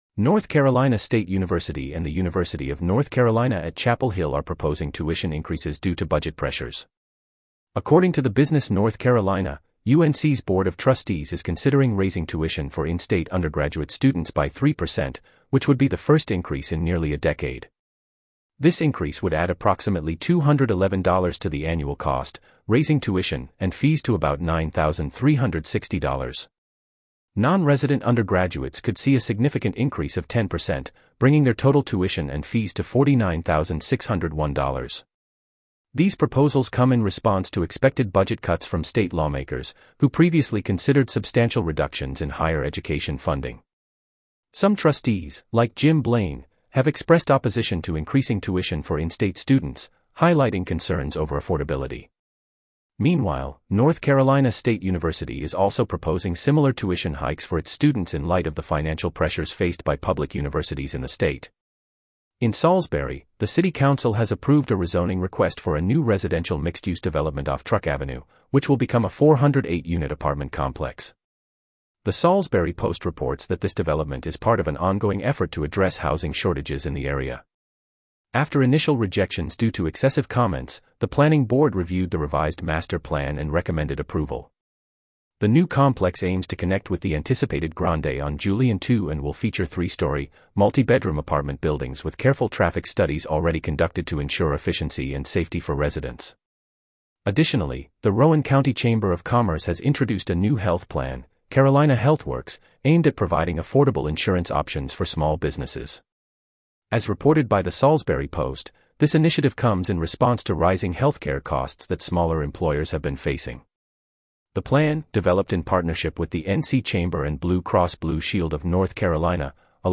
North Carolina News Summary
Regional News